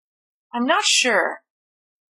x_sure.mp3